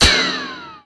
rifle_hit_altmetal1.wav